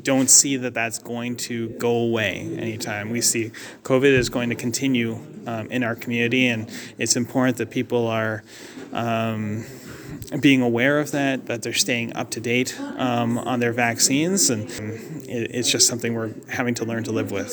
Hastings Prince Edward Medical Officer of Health, Dr Ethan Toumishey, told the Board of Health on Wednesday that he doesn’t see it going away anytime soon.